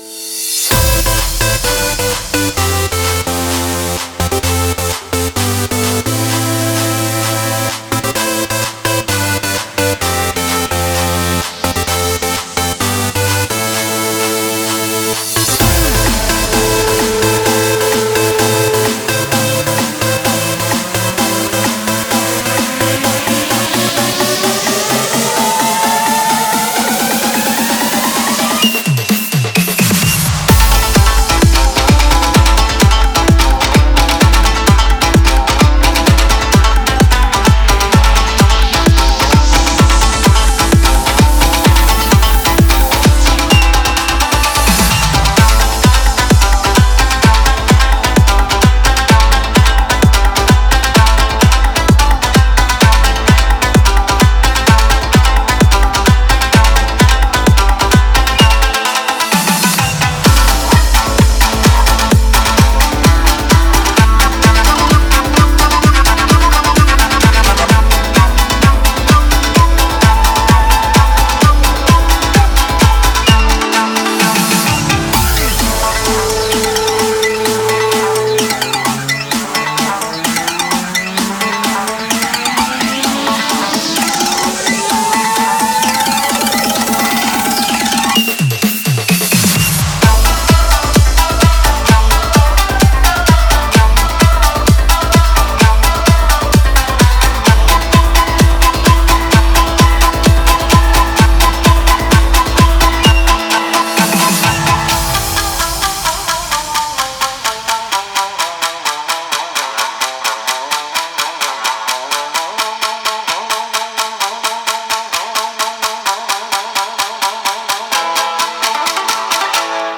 BPM129
Audio QualityPerfect (High Quality)
Comentarios[J-TRADITIONAL EDM]